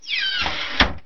default_use_chest.ogg